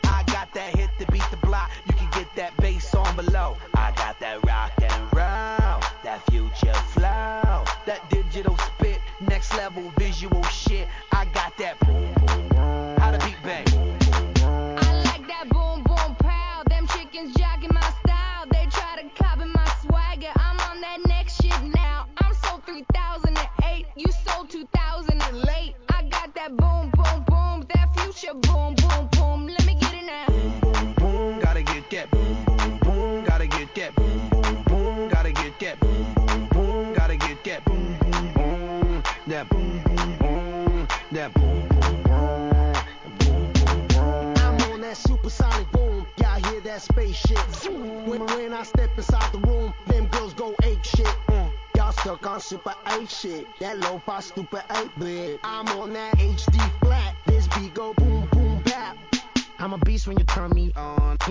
HIP HOP/R&B
大胆にエレクトロを導入した超絶ダンス・アルバムとなった2009年作品!!